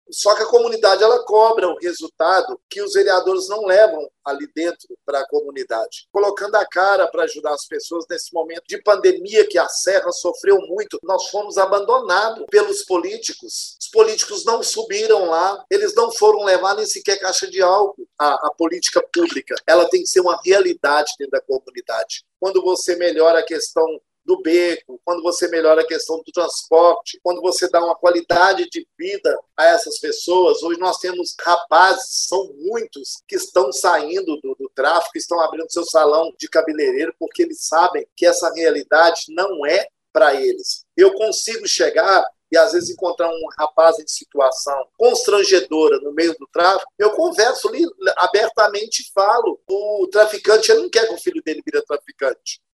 Vereador Gilson Guimarães, sobre a falta de presença estatal na região